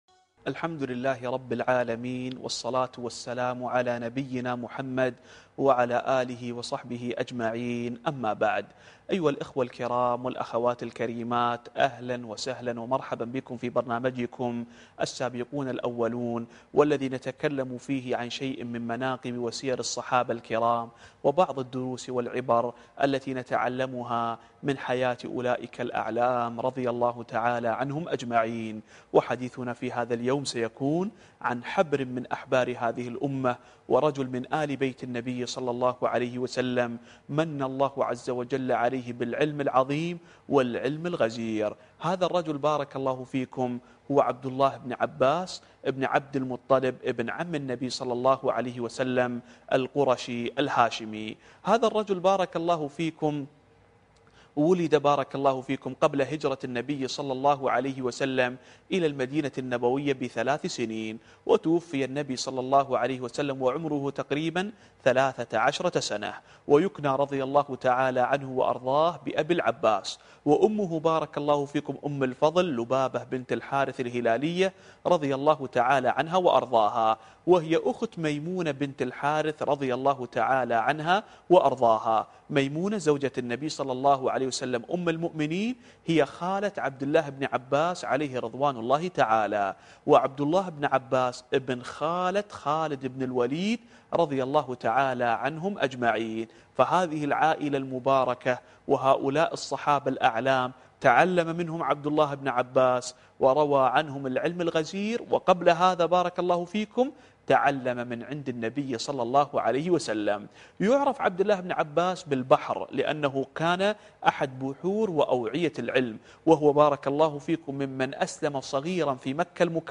الدرس السادس والعشرون